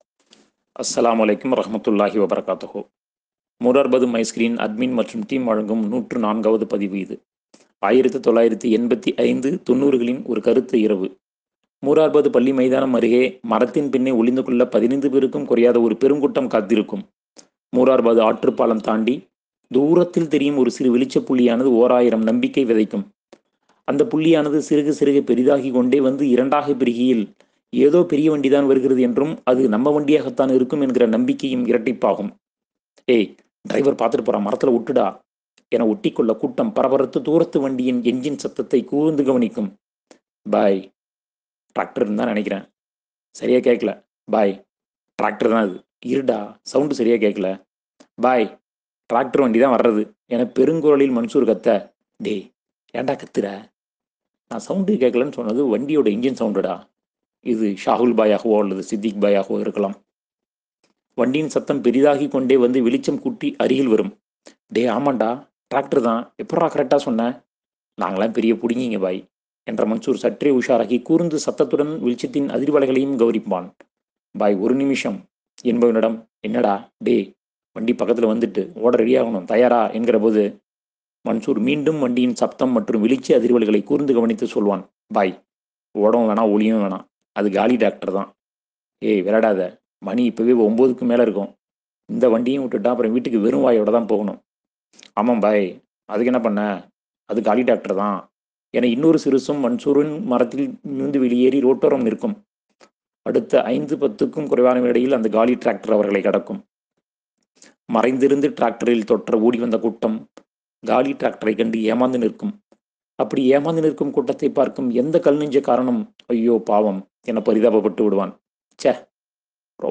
கரும்பு.. இனிப்பா – கசப்பா கட்டுரையின் குரல் ஒலிப்பதிவு இது